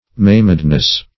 \maim"ed*ness\